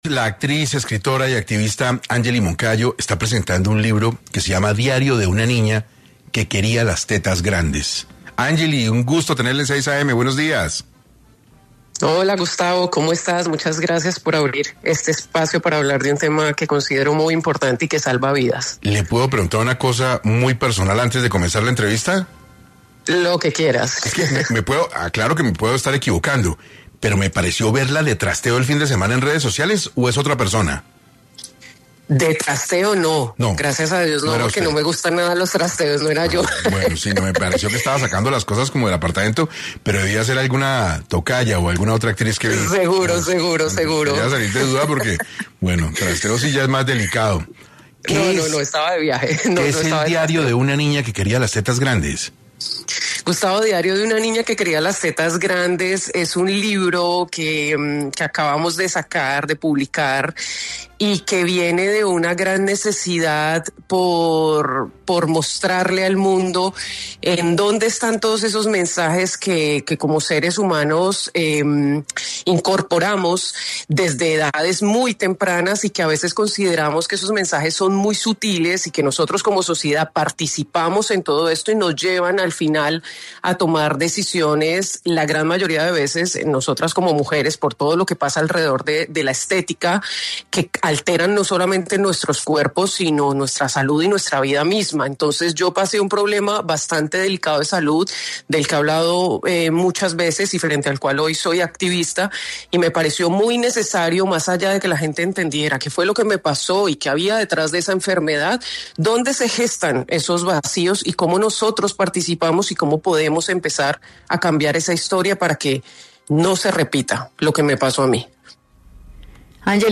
En 6AM habló Angelly Moncayo, actriz, sobre las reflexiones a las que invita su reciente libro “Diario de una niña que quería las tetas grandes”.